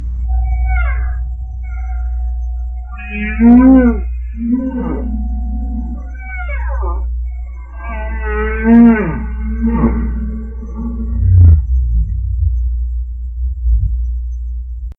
Download a humpback whale song ringtone
Humpback.mp3